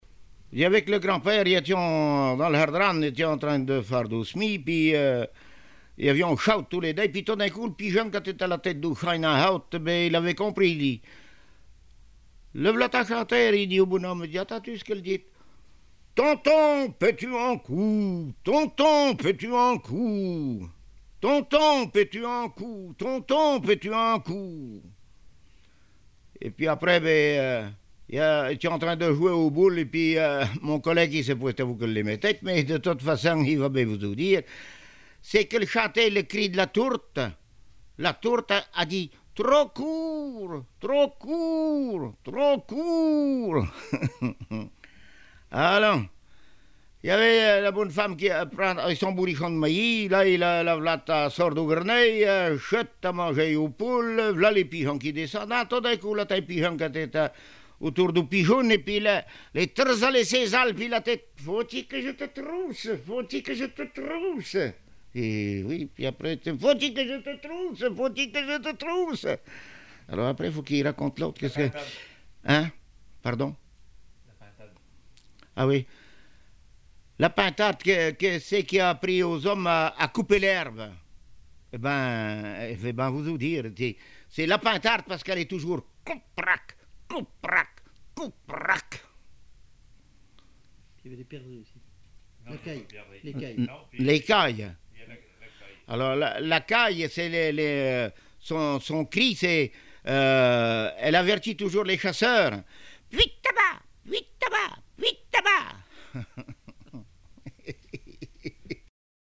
Le cri de la tourte et du pigeon
Localisation Saint-Hilaire-de-Riez
Langue Maraîchin
Genre sketch